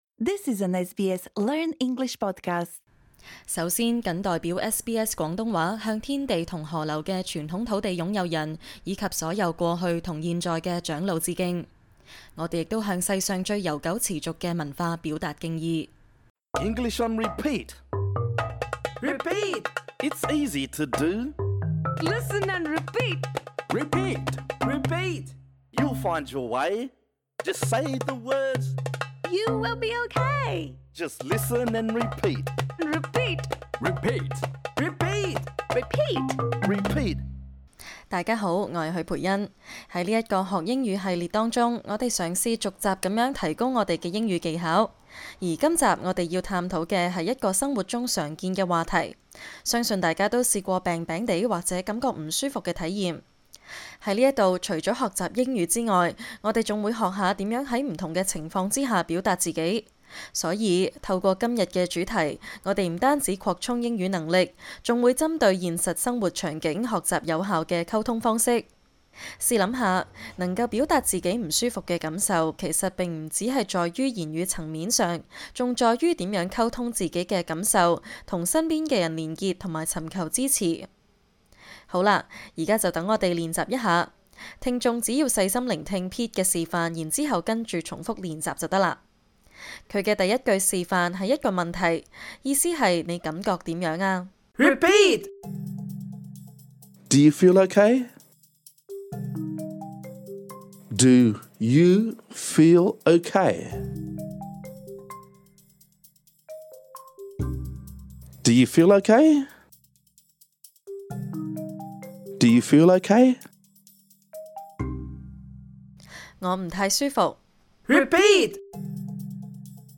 這個教學專為初學者而設。